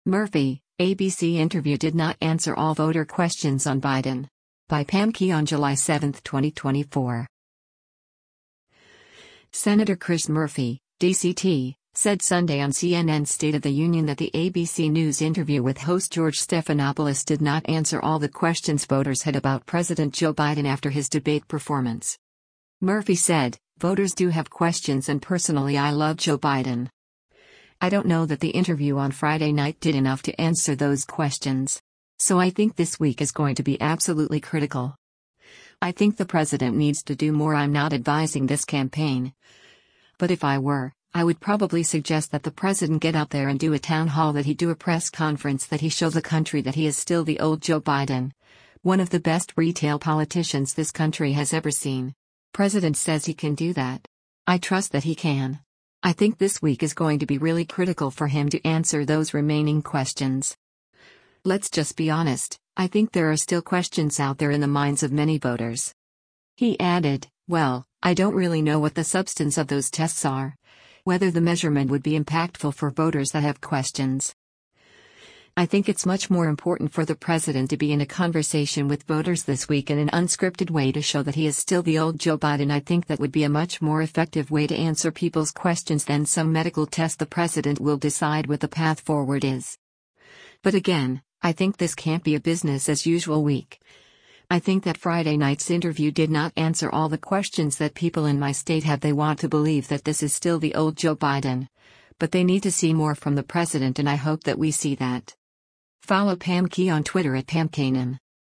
Senator Chris Murphy (D-CT) said Sunday on CNN’s “State of the Union” that the ABC News interview with host George Stephanopoulos did not answer all the questions voters had about President Joe Biden after his debate performance.